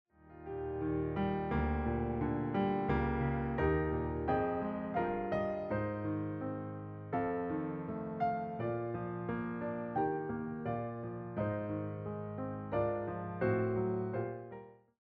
reimagined as solo piano pieces